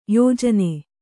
♪ yōjane